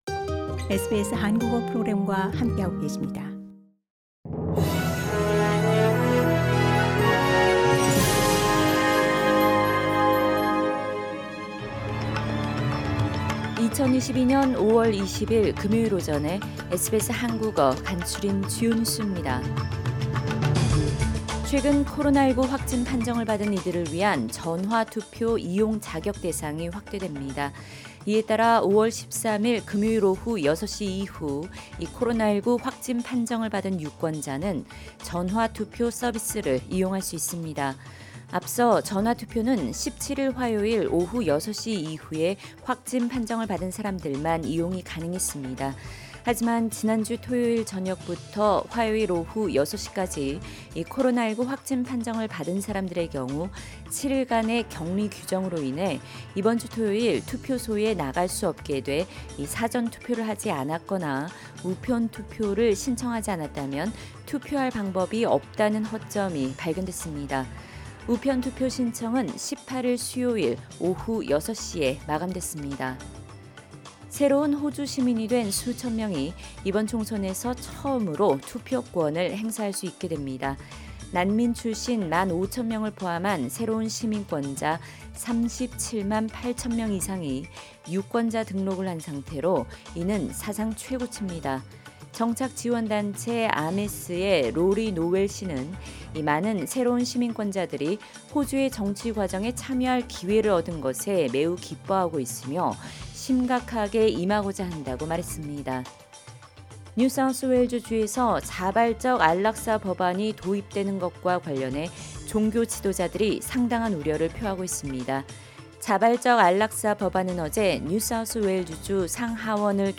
2022년 5월 20일 금요일 아침 SBS 한국어 간추린 주요 뉴스입니다.